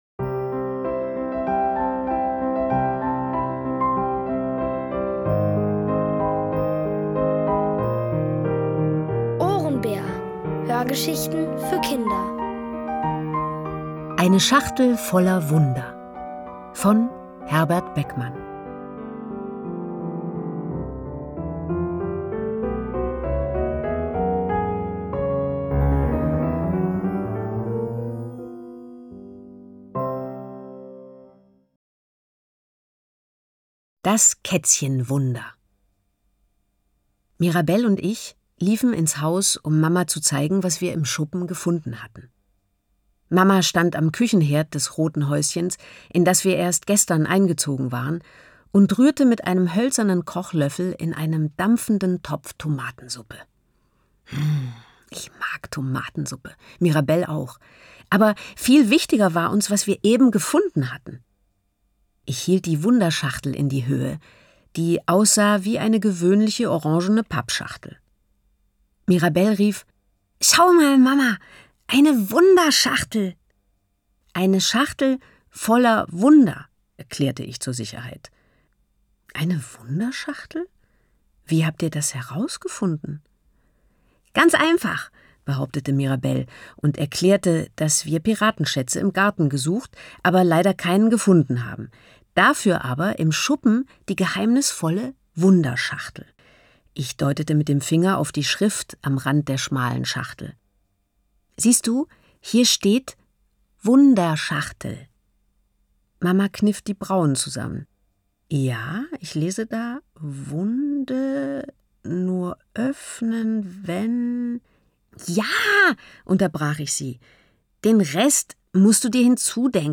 Von Autoren extra für die Reihe geschrieben und von bekannten Schauspielern gelesen.
liest: Nina Hoss.